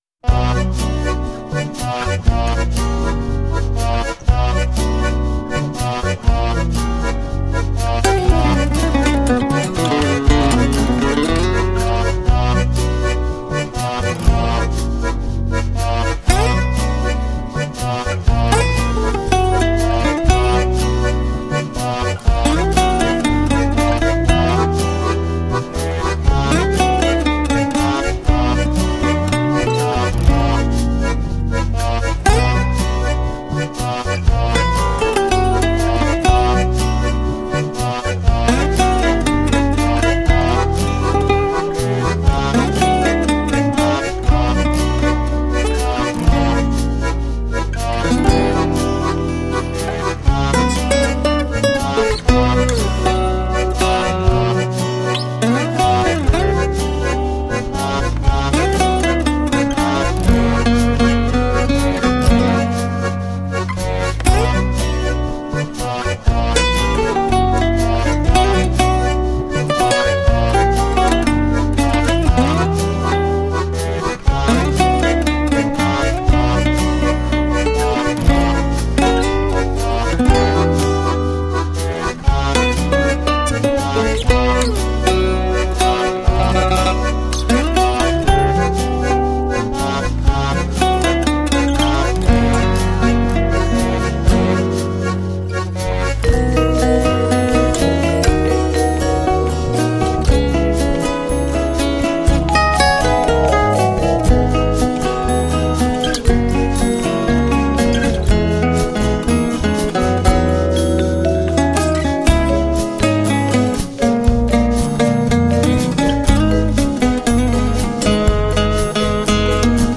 GenereJazz